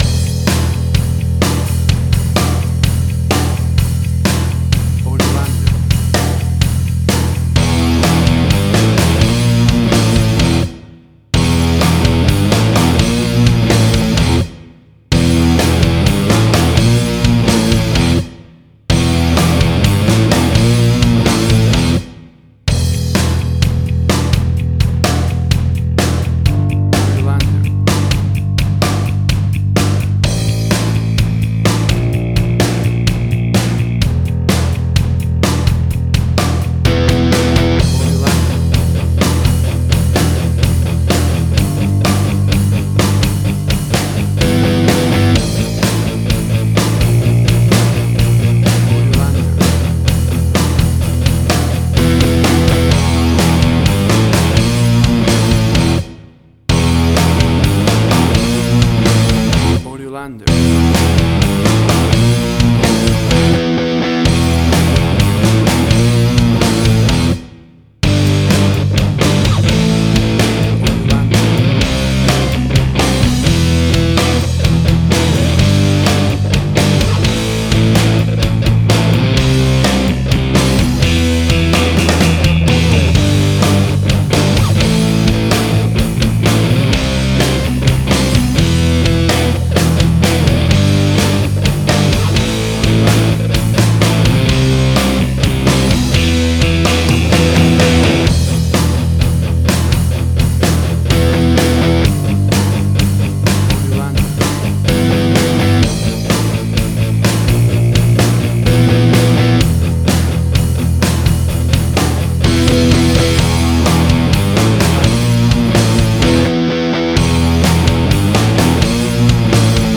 Hard Rock
Heavy Metal.
WAV Sample Rate: 16-Bit stereo, 44.1 kHz
Tempo (BPM): 127